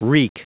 Prononciation du mot reek en anglais (fichier audio)
Prononciation du mot : reek